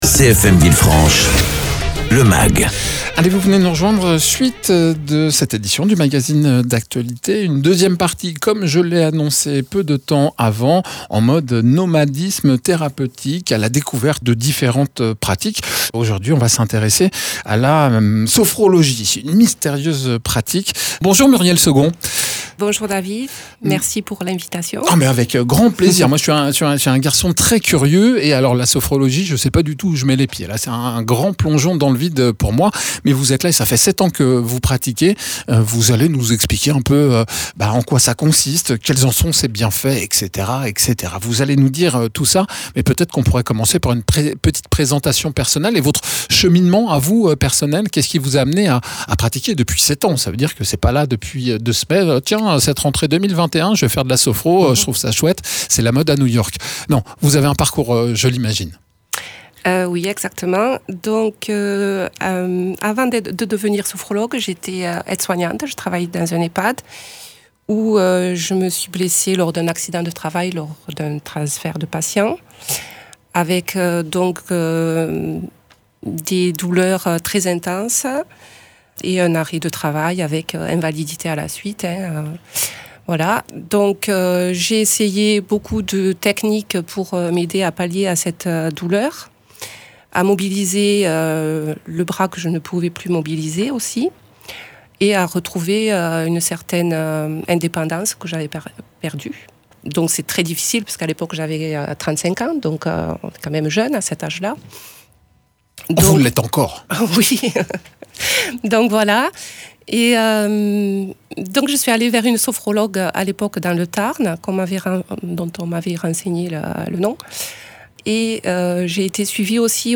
Entretien avec une praticienne en activité depuis 7 ans. Technique thérapeutique, philosophie de vie ?
Interviews